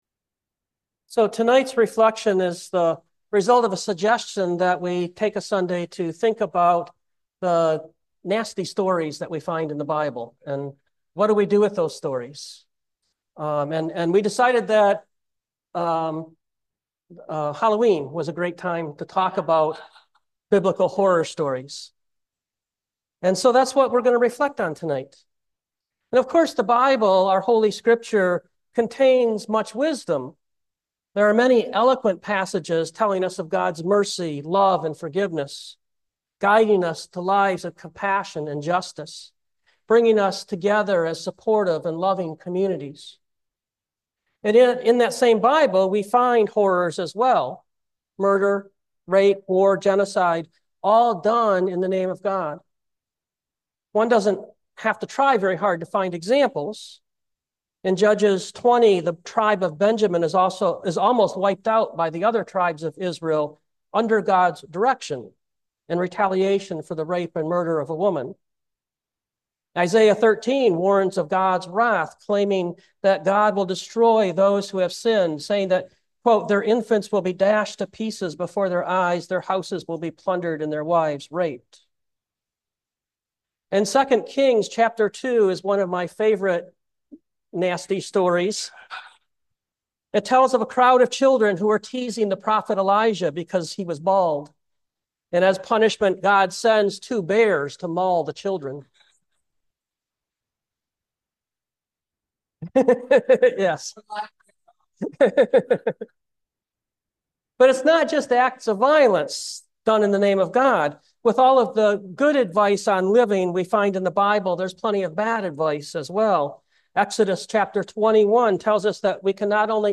2024 Biblical Horrors Preacher